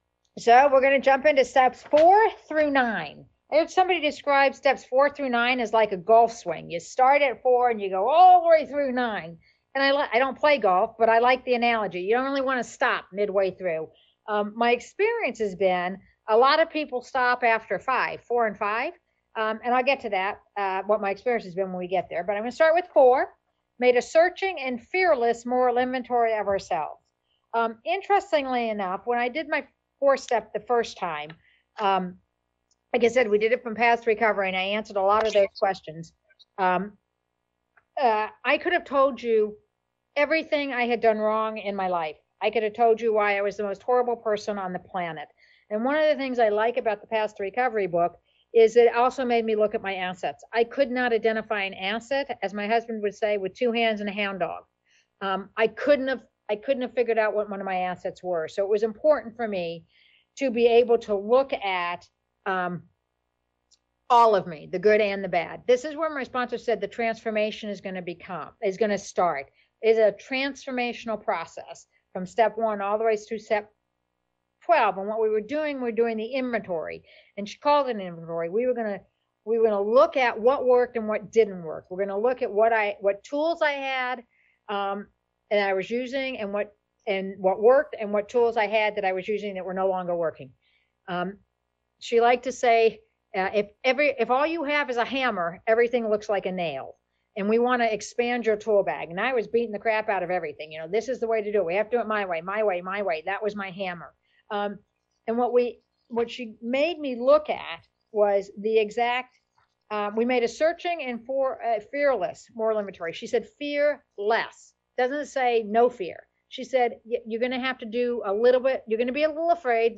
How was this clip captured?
Idaho District 3 Fundraiser - Al-Anon Steps Come Alive